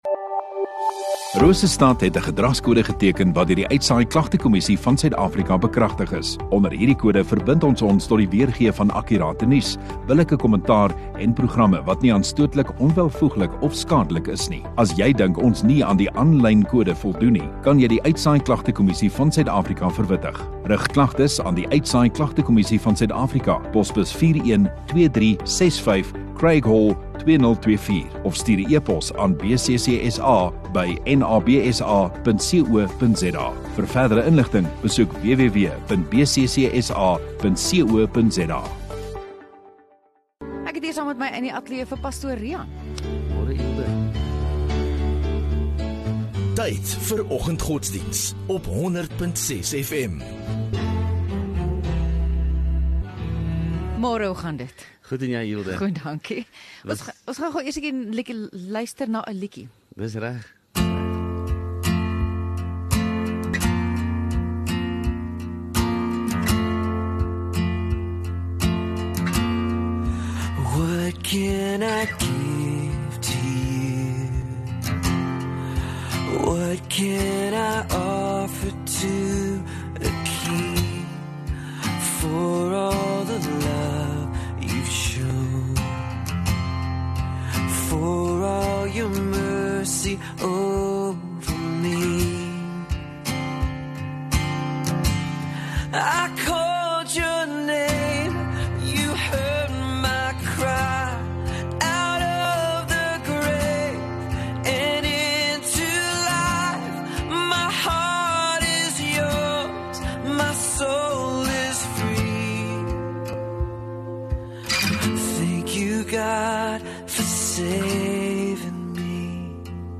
28 Nov Vrydag Oggenddiens